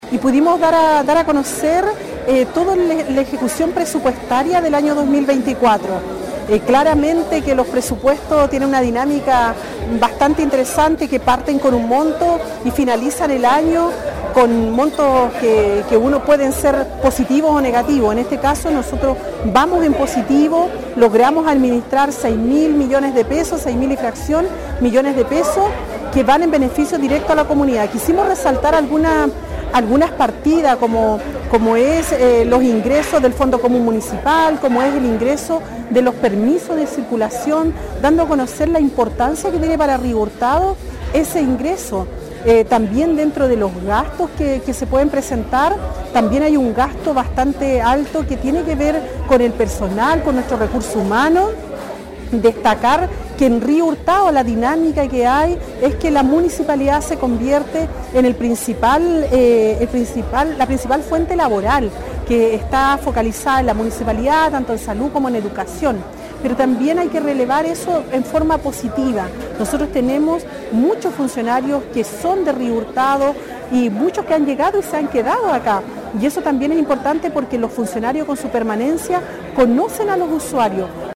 Alcaldesa rinde Cuenta Pública gestión 2024